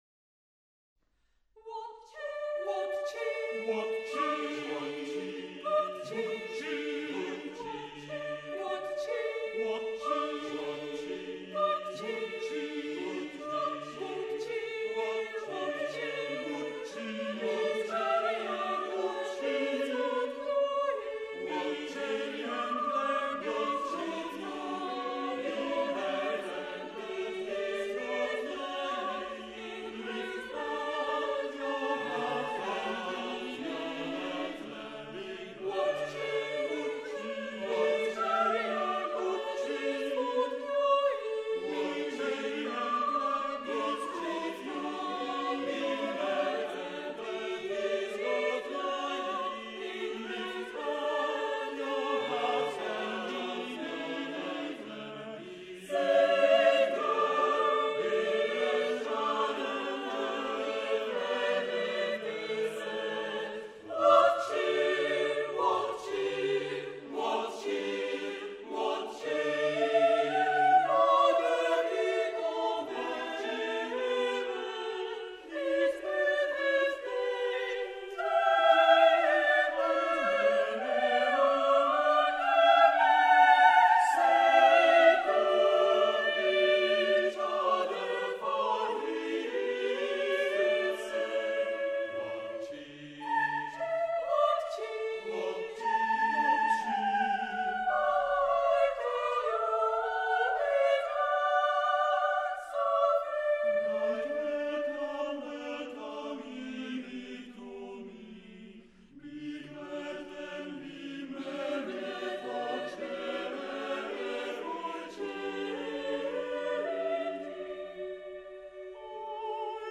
Choir
(2006) (SATB)